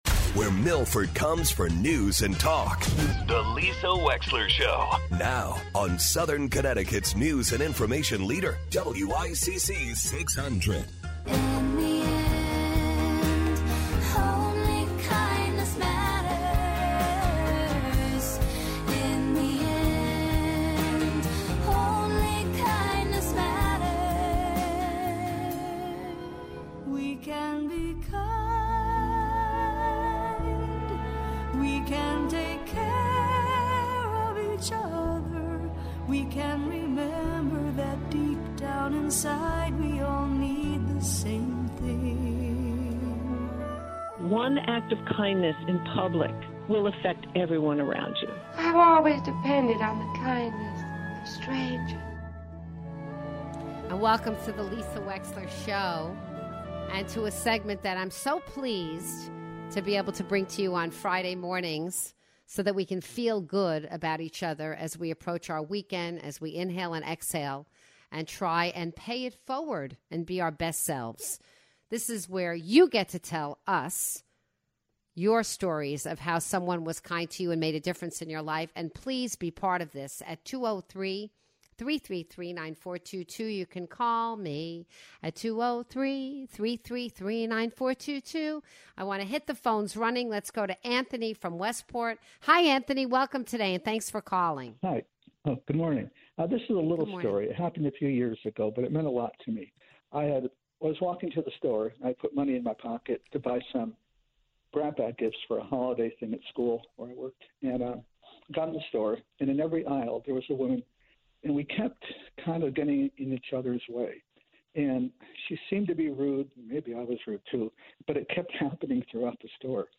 8/21/21 The Kindness of Strangers: Ep. 2 : Callers tell stories of when a stranger's kindness changed their life.